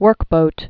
(wûrkbōt)